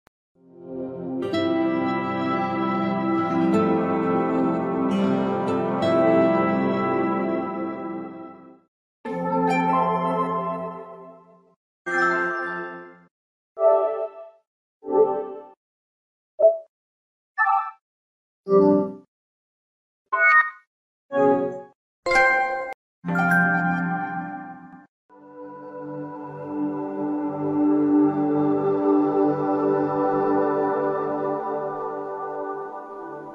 Step into the gaming world with PlayStation 4 Sounds!